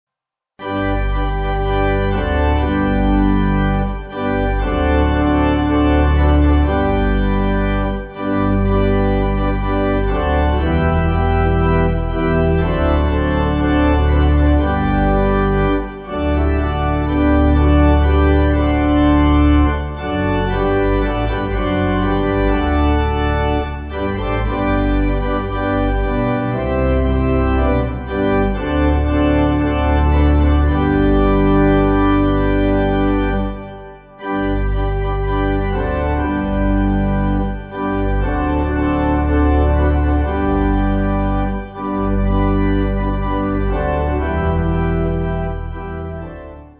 (CM)   4/G